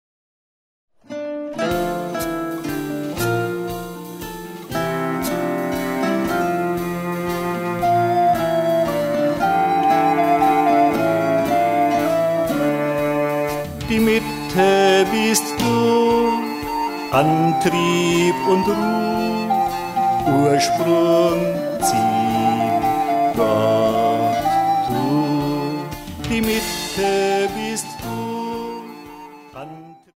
Meditativer Gesang (Mantra)